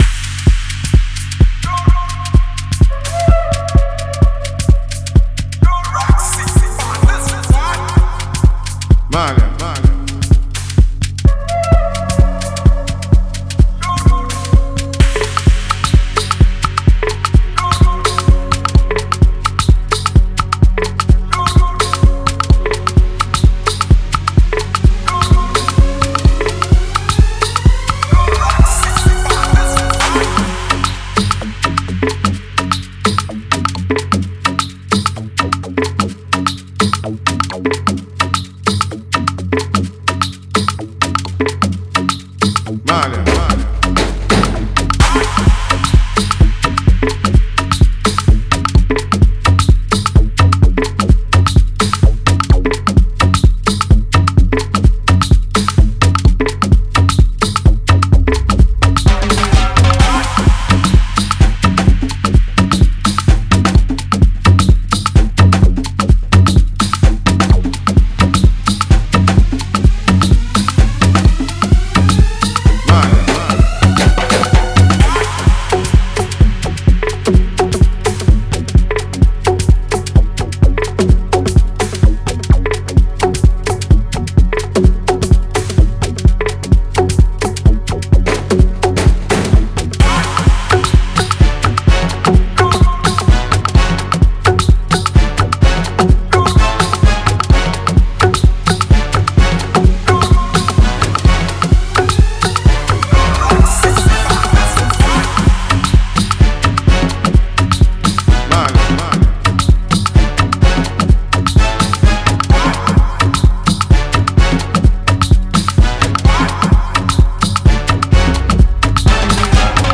Free beat
Nigerian beatsmith